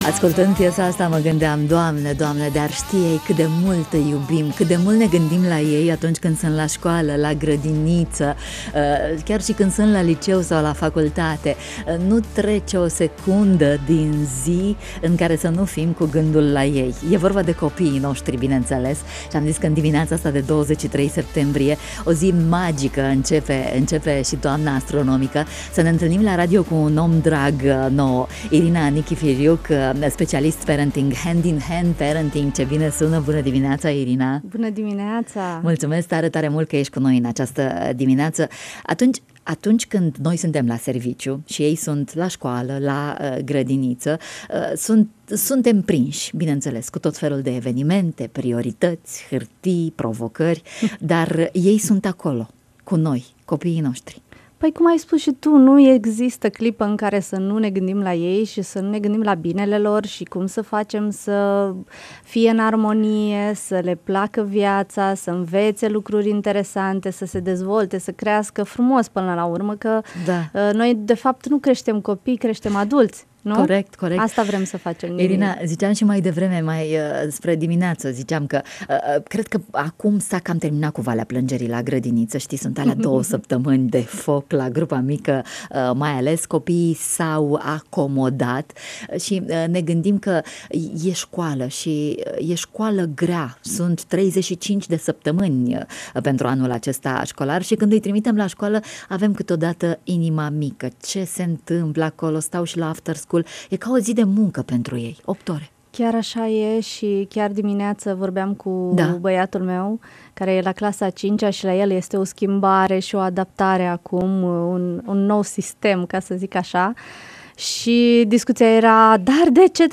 Despre relaţii armonioase şi senine cu copiii noştri, în matinalul Radio România Iaşi.